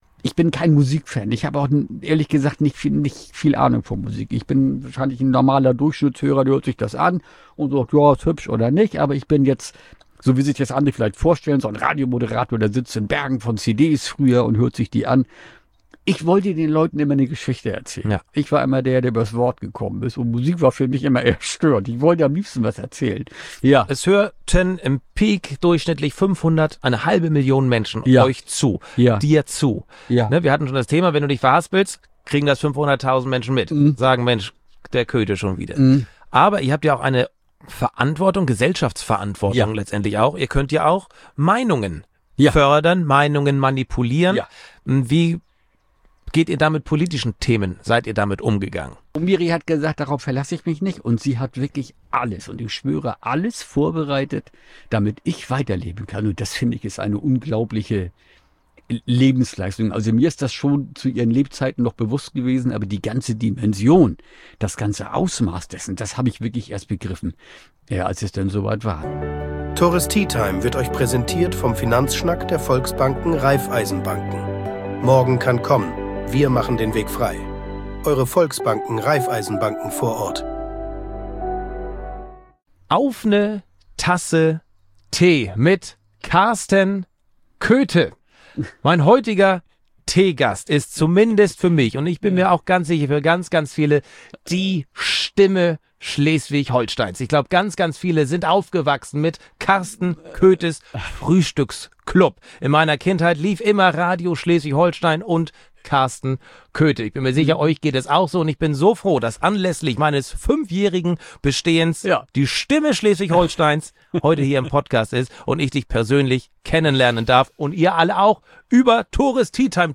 Hört mal rein in dieses interessante und emotionale Gespräch.